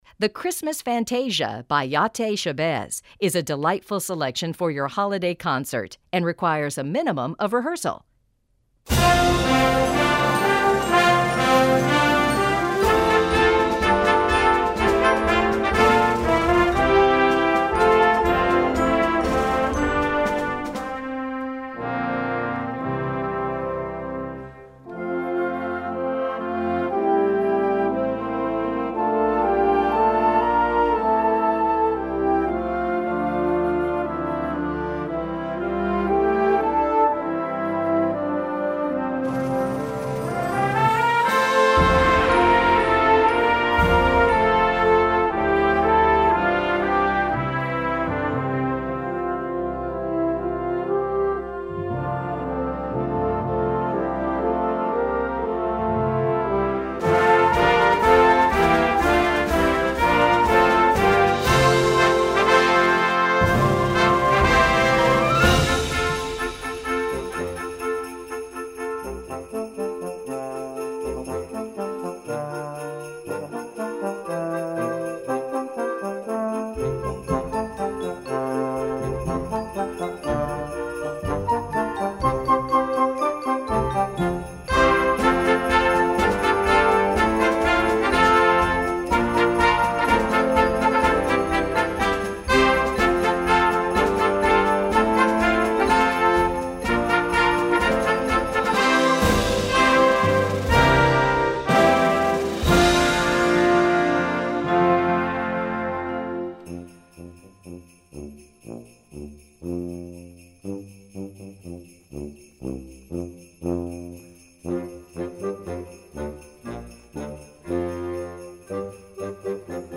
Gattung: Konzertstück
Besetzung: Blasorchester
eine Ouvertüre für Blasorchester
frechen und frischen neuen Harmonien